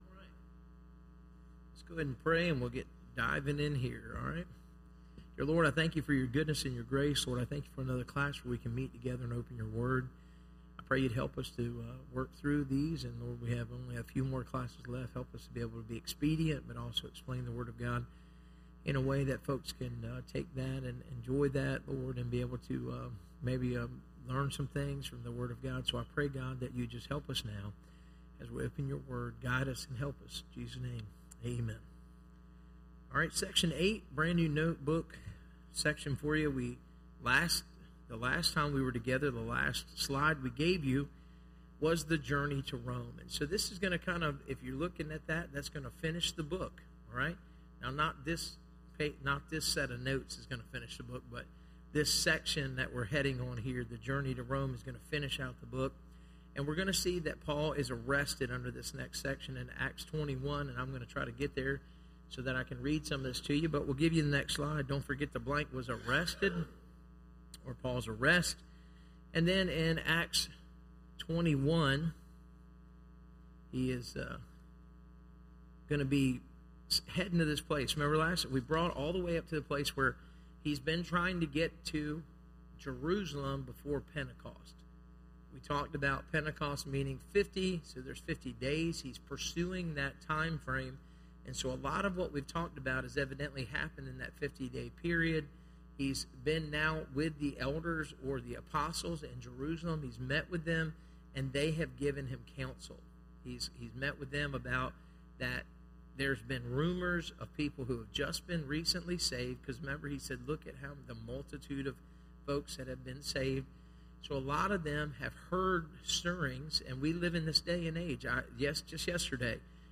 Service Type: Institute